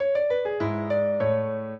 piano
minuet5-11.wav